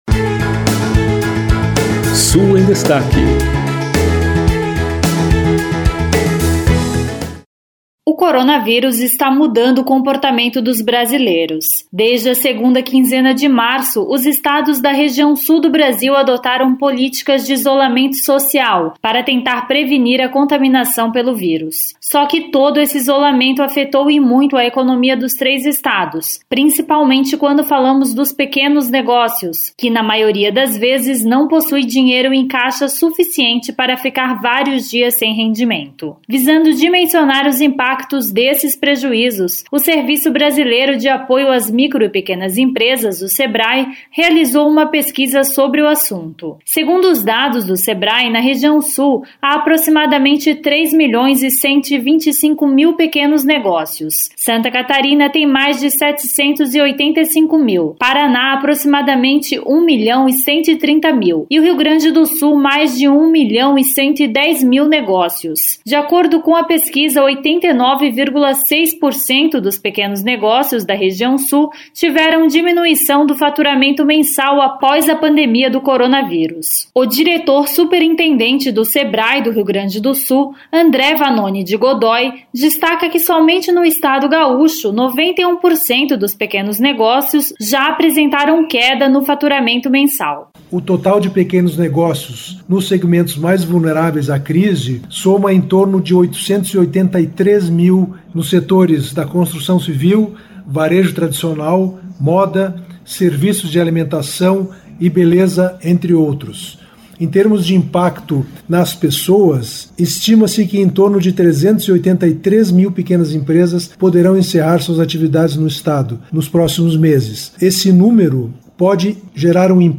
De Florianópolis, repórter